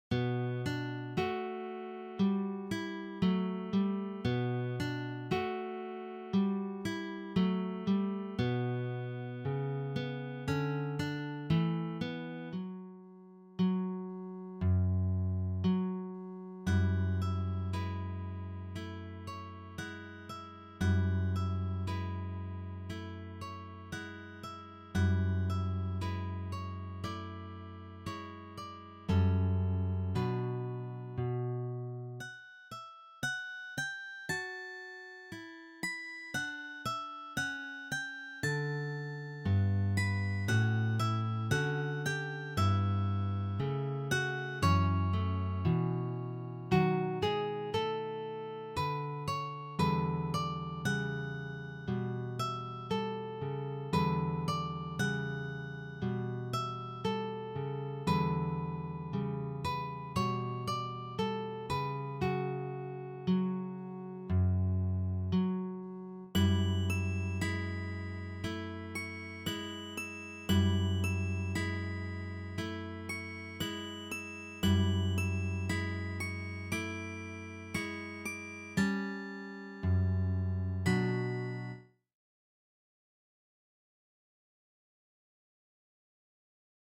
Guitar Quartet